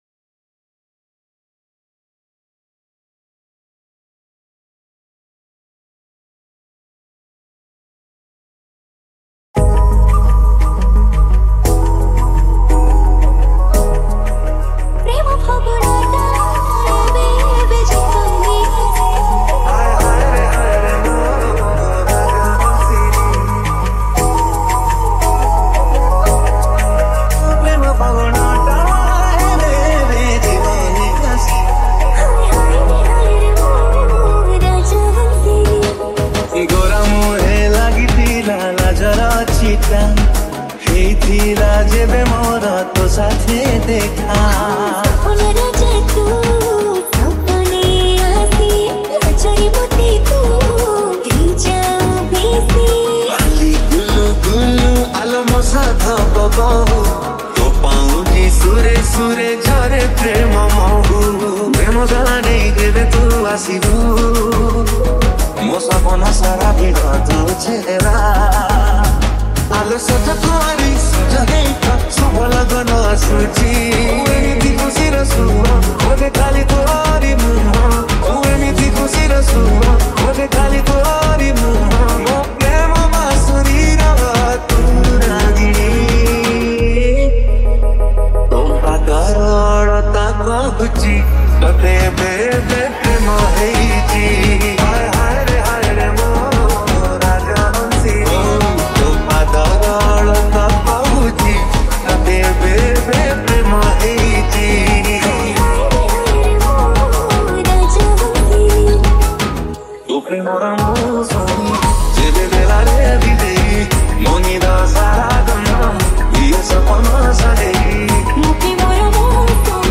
MASHUP 2022 Songs Download